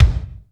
KIK F T K03R.wav